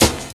100 NOISE SN.wav